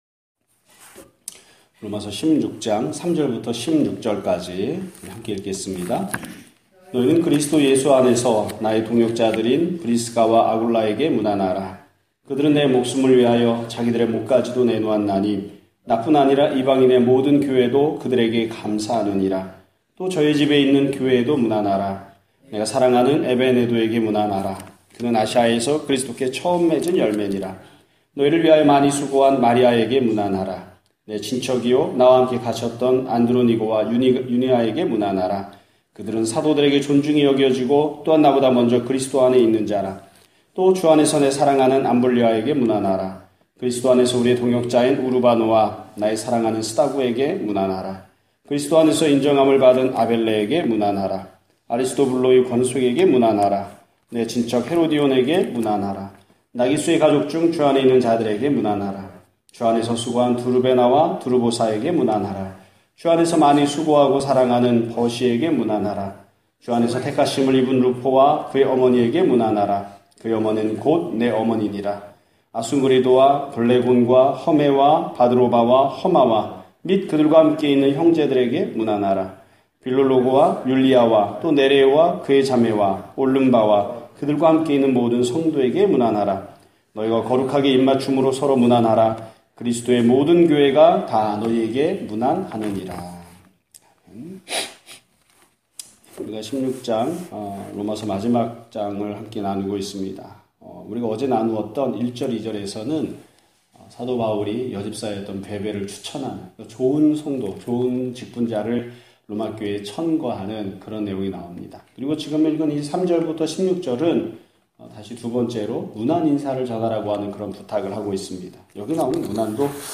2017년 7월 20일(목요일) <아침예배> 설교입니다.